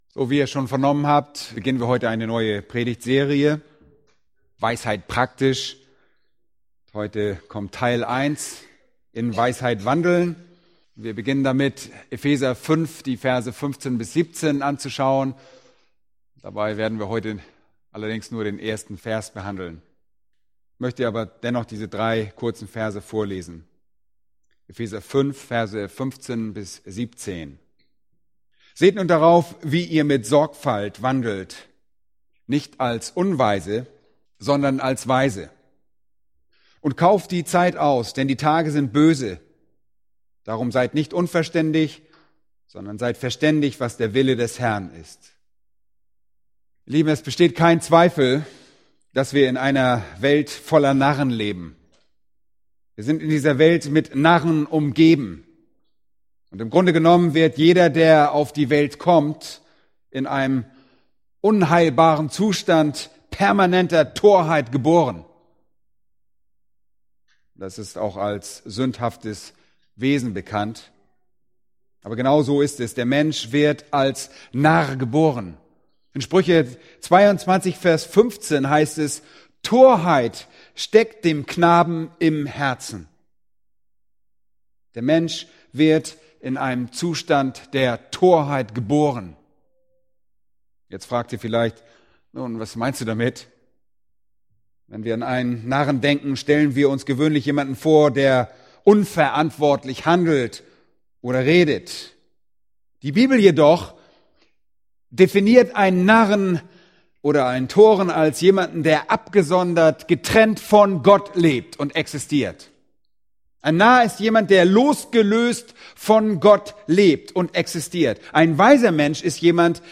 Eine predigt aus der serie "Weisheit Praktisch*." Epheser 5,15